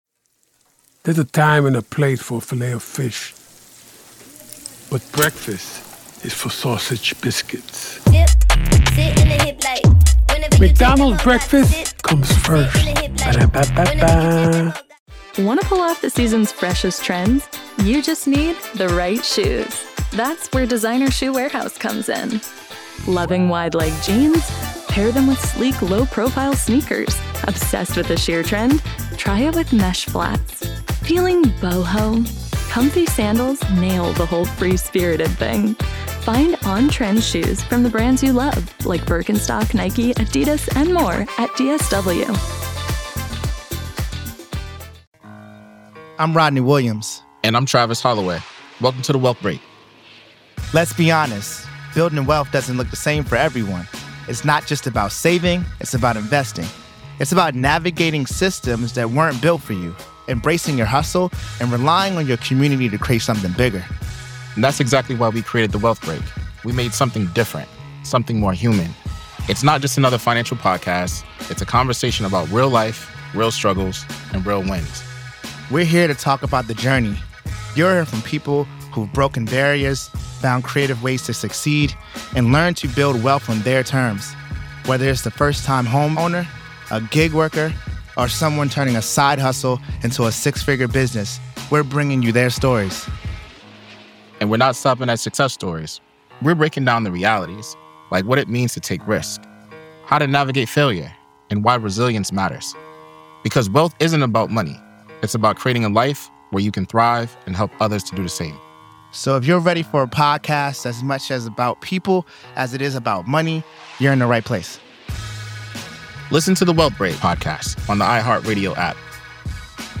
This raises questions about whether Rader is hinting at involvement in other unsolved crimes. The hosts discussed the possibility that Rader might be seeking attention once again.